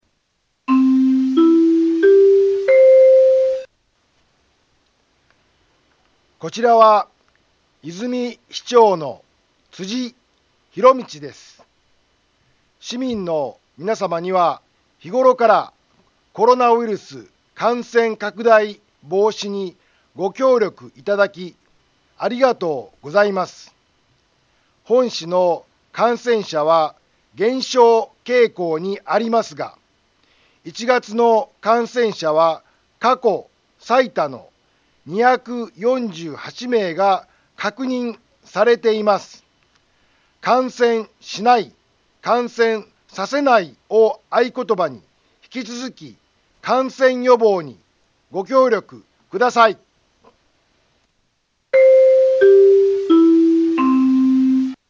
Back Home 災害情報 音声放送 再生 災害情報 カテゴリ：通常放送 住所：大阪府和泉市府中町２丁目７−５ インフォメーション：こちらは、和泉市長の辻 ひろみちです。